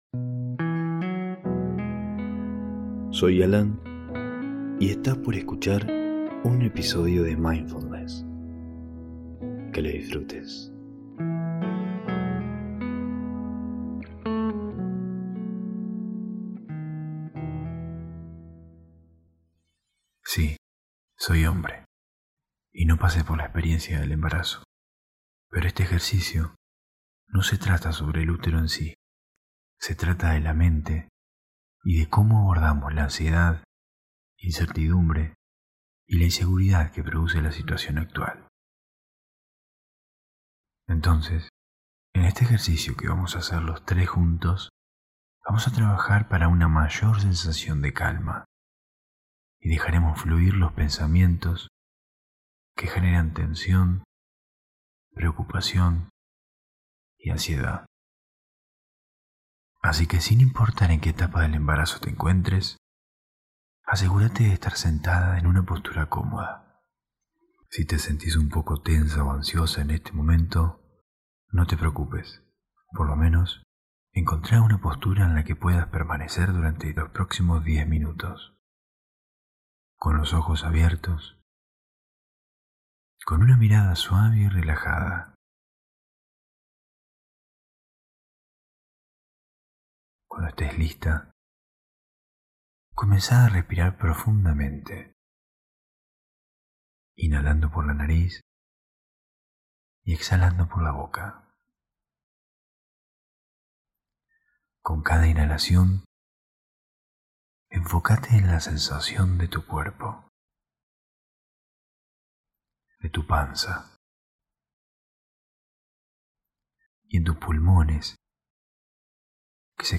Meditación para embarazadas que viven la situación actual con ansiedad, tensión y preocupación.